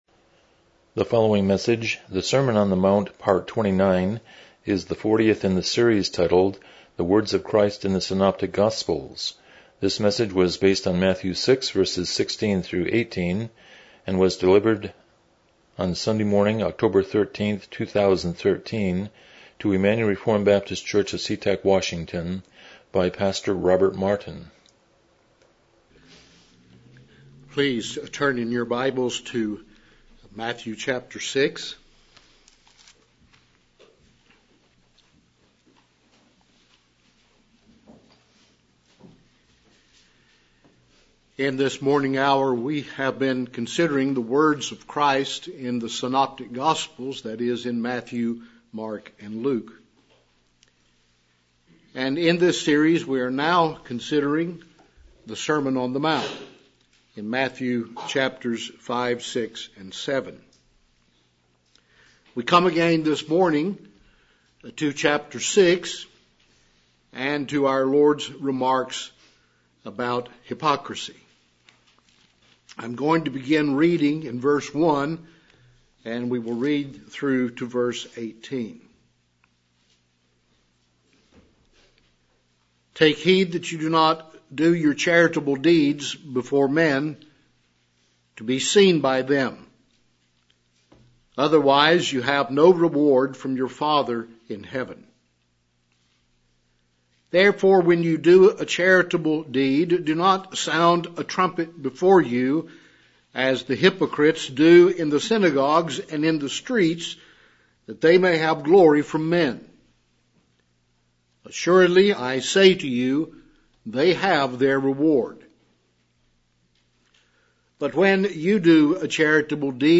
Passage: Matthew 6:16-18 Service Type: Morning Worship